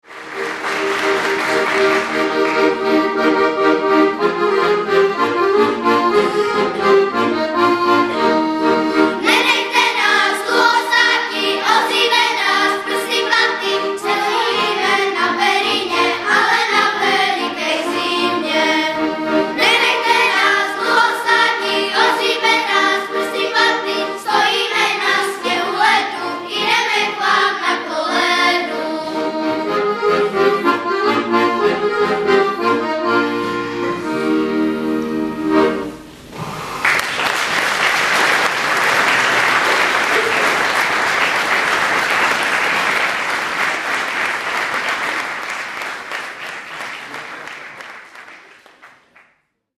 ● zvukový záznam bystřického vánočního koncertu ●